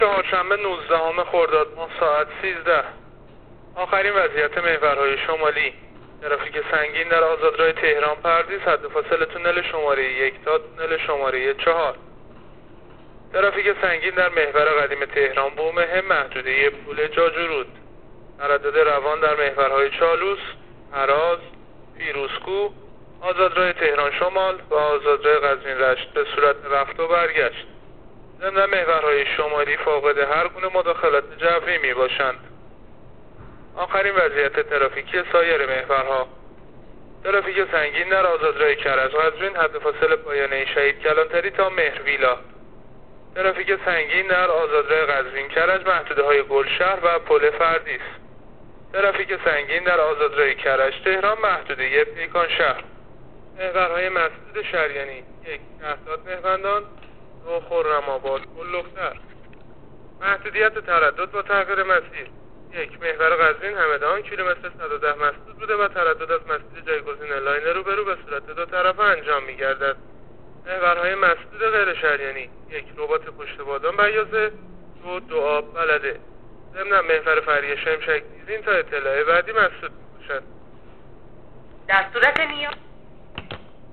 گزارش رادیو اینترنتی از آخرین وضعیت ترافیکی جاده‌ها تا ساعت ۱۳ نوزدهم خرداد